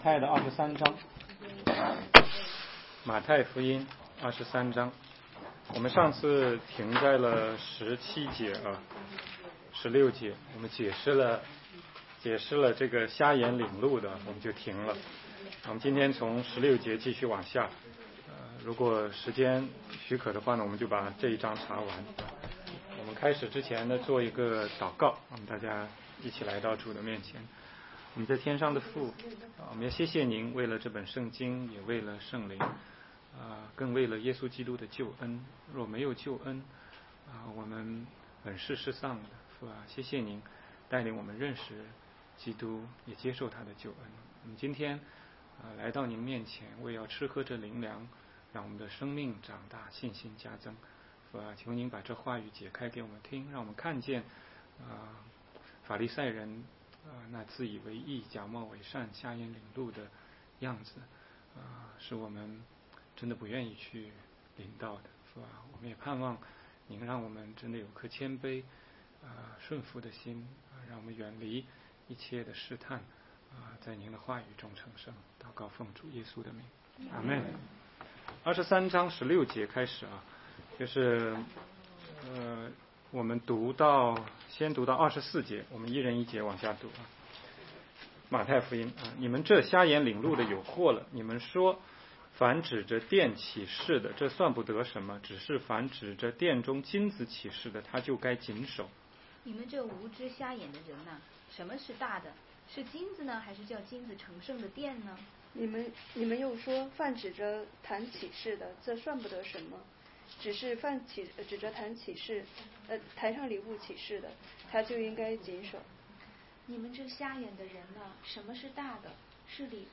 16街讲道录音 - 马太福音23章16-39节：法利赛人有祸了